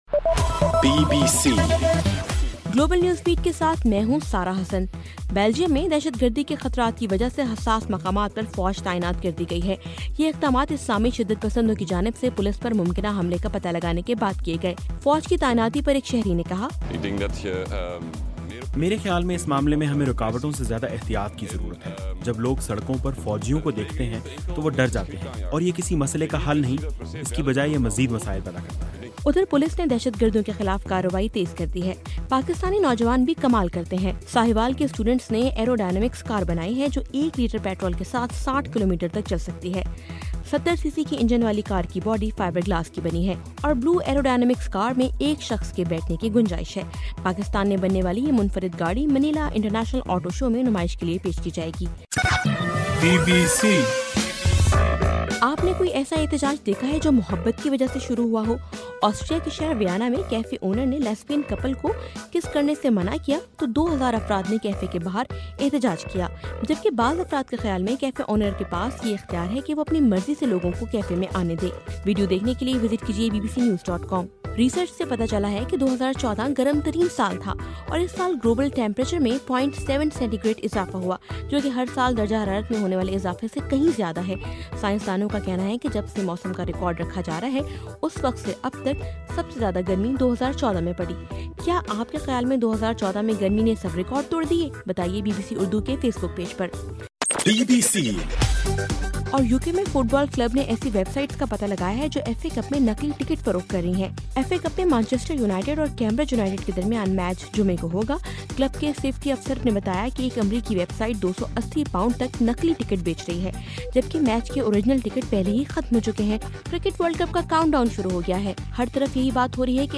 جنوری 18: رات 12 بجے کا گلوبل نیوز بیٹ بُلیٹن